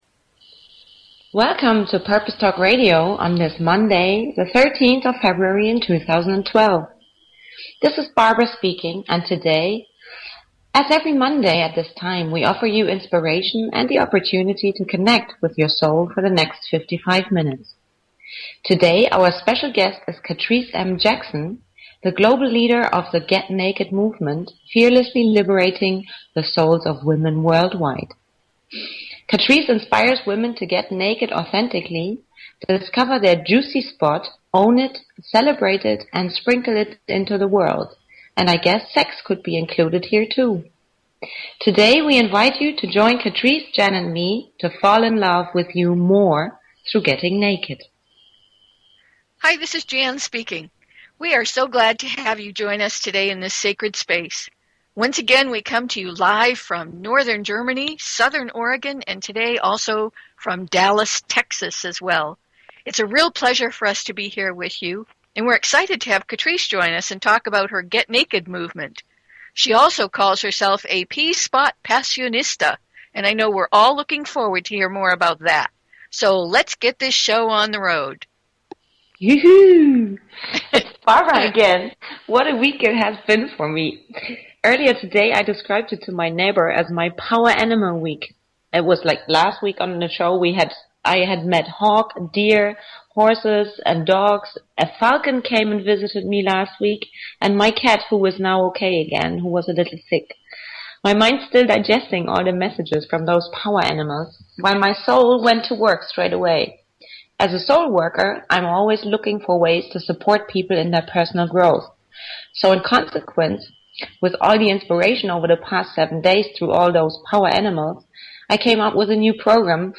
Talk Show Episode, Audio Podcast, Purpose_Talk_Radio and Courtesy of BBS Radio on , show guests , about , categorized as